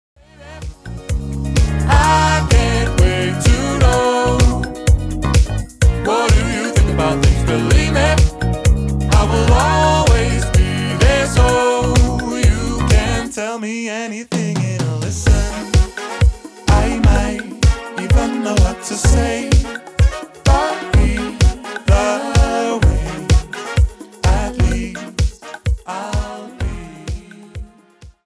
• Качество: 320, Stereo
поп
позитивные
мужской вокал
диско
Synth Pop
заводные
легкие